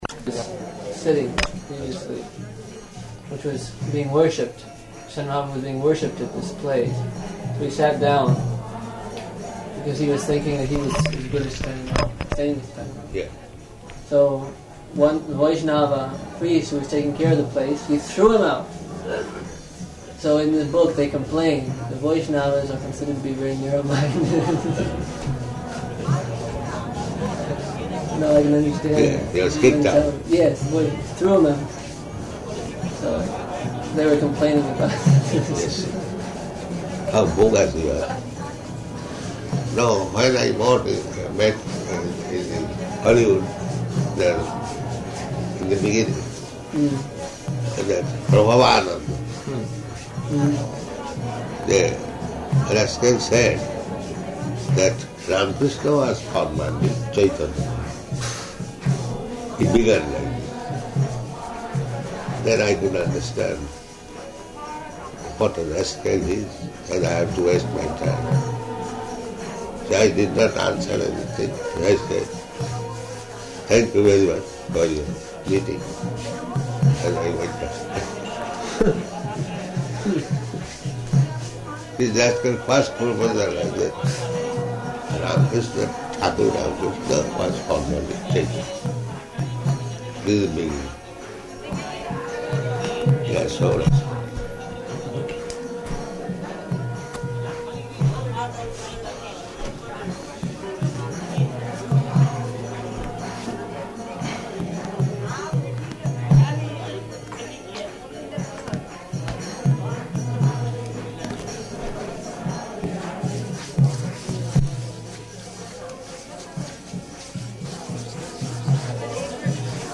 Type: Lectures and Addresses
Location: London
[ kīrtana in background]